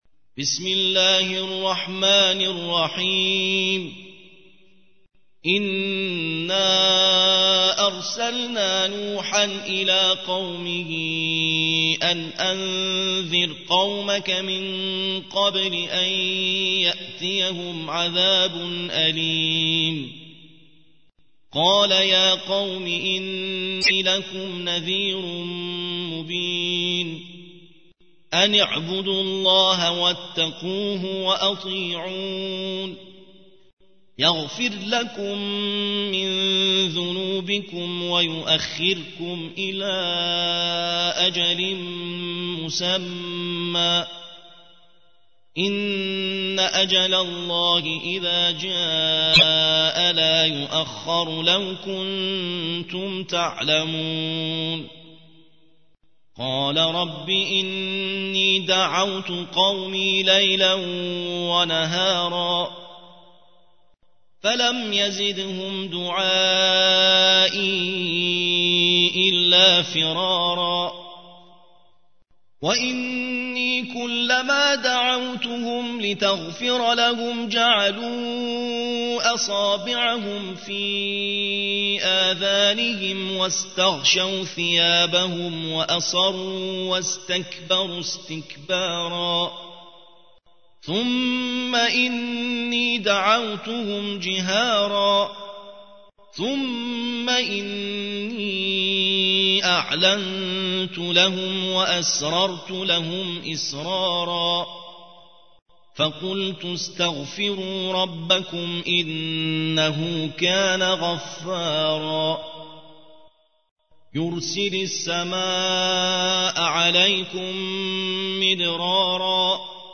71. سورة نوح / القارئ